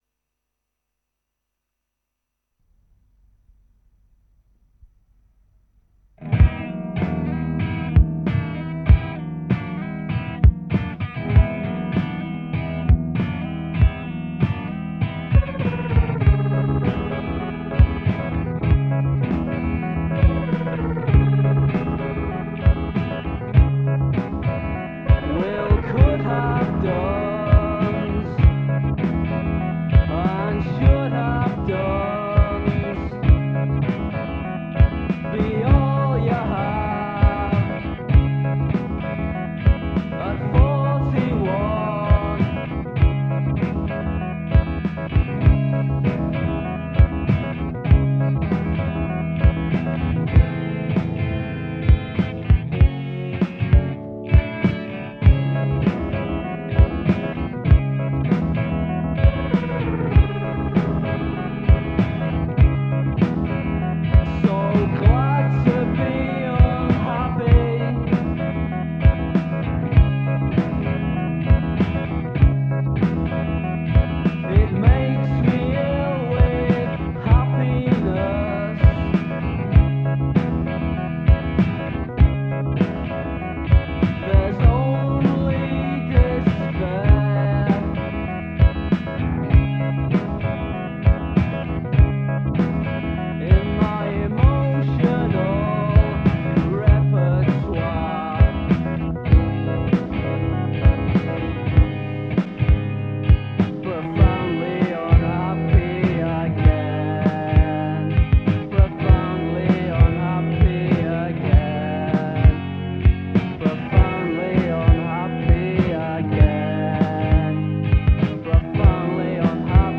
guitar, vocals
bass guitar
drums